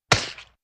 splat1.ogg